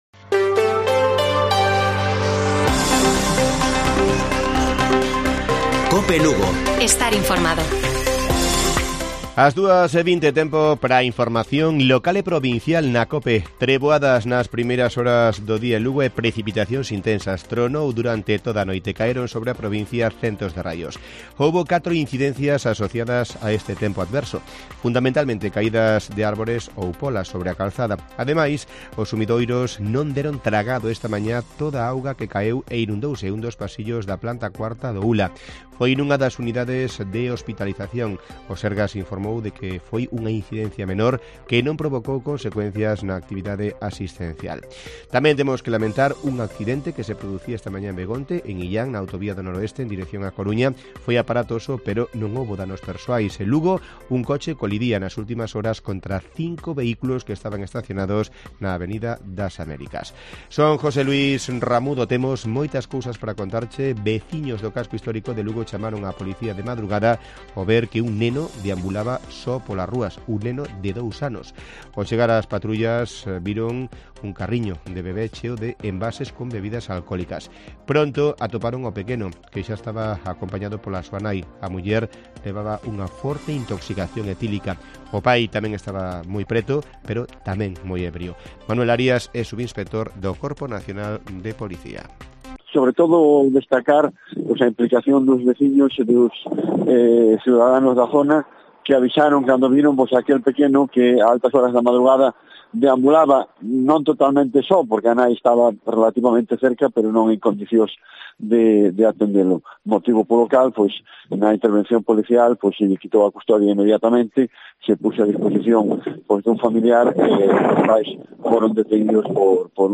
Informativo Mediodía de Cope Lugo. 19 DE OCTUBRE. 14:20 horas